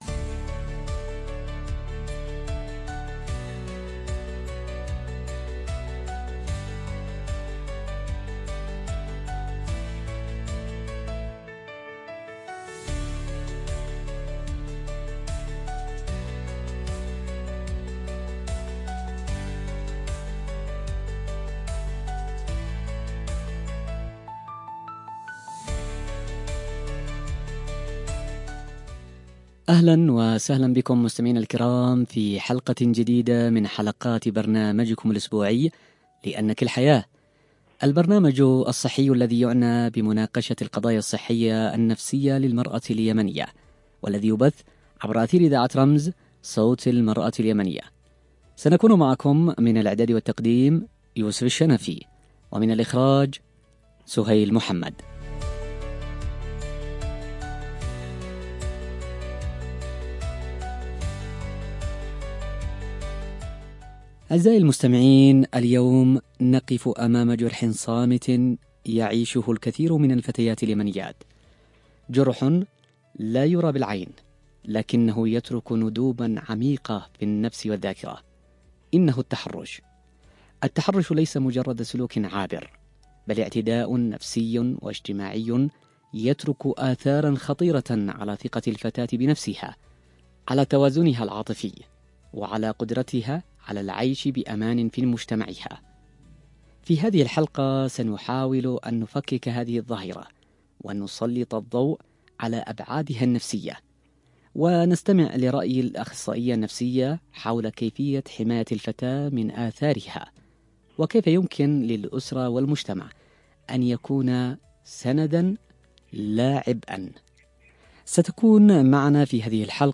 📻 عبر أثير إذاعة رمز